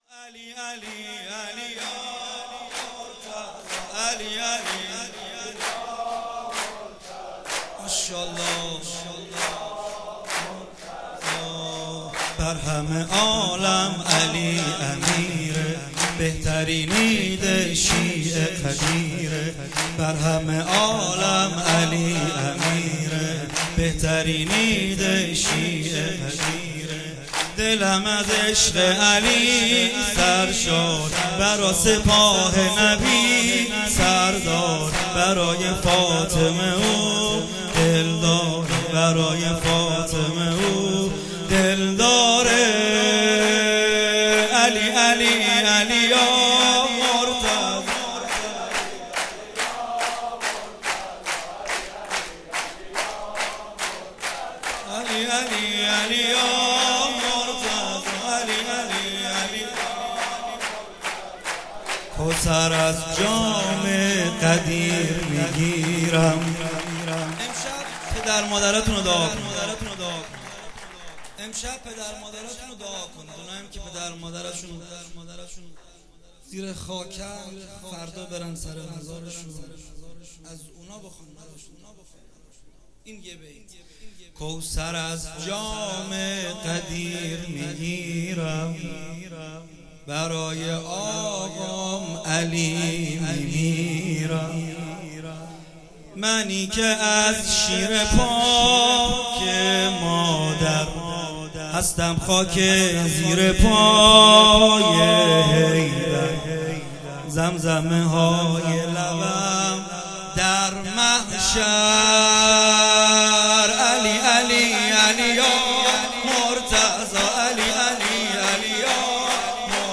مراسم عید غدیر 16 شهریور 96
چهاراه شهید شیرودی حسینیه حضرت زینب (سلام الله علیها)
سرود